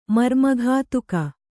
♪ marmaghātuka